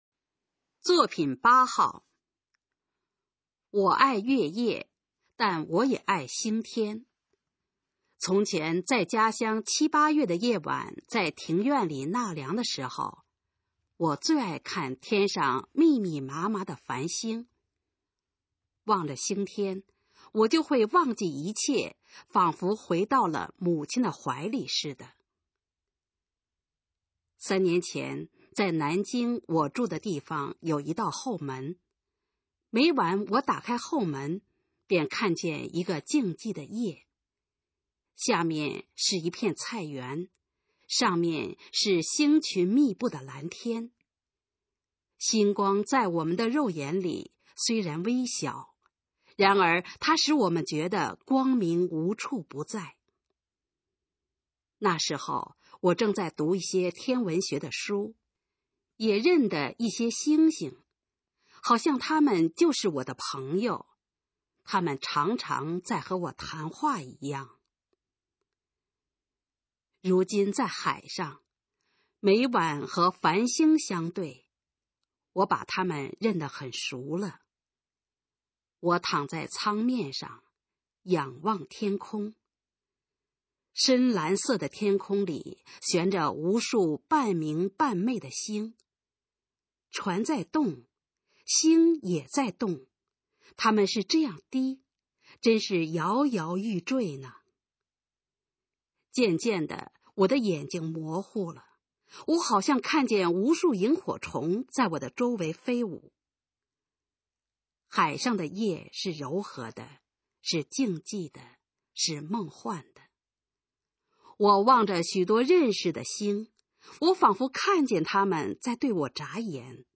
首页 视听 学说普通话 作品朗读（新大纲）
《繁星》示范朗读_水平测试（等级考试）用60篇朗读作品范读